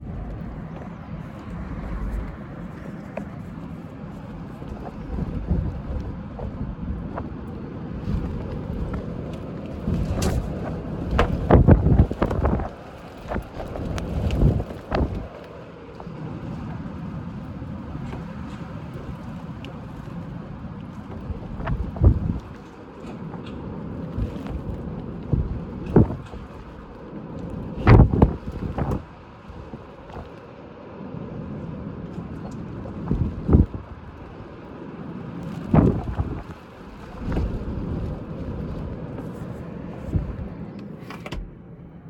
It sounds like we live on a really busy road doesn't it? In reality there's no cars outside and the roads are empty.
Just holding the phone at my window - not even fully open - and this is while it's fairly calm in comparison to when it really whips up.
It comes in big waves but that low level wind noise is just constant.